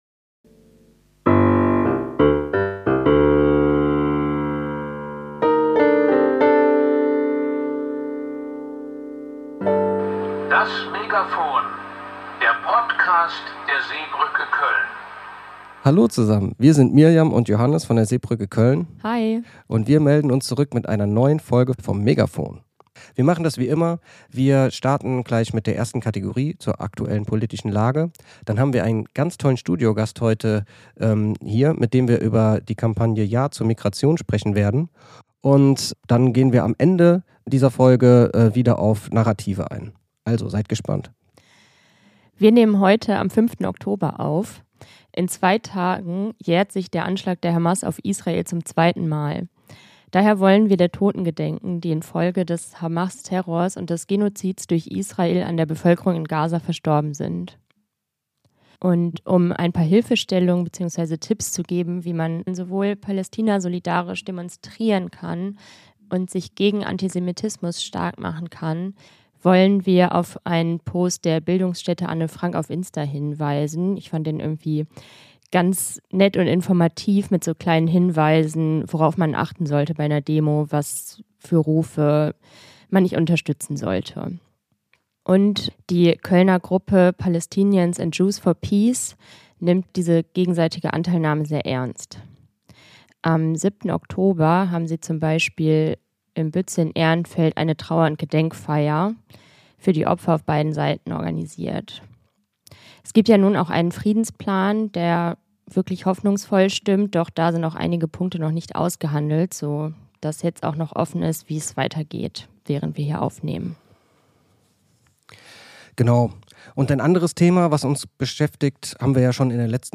Im Thema des Tages sprechen wir heute, mit einem Studiogast, zur Kampagne 'Ja zu Migration' die in den letzten Wochen in Köln aktiv war.